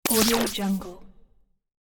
دانلود افکت صدای اتصال برقی ۱
• صداهای جرقه زدن و قوس الکتریکی
• صداهای قطع و وصل جریان برق
16-Bit Stereo, 44.1 kHz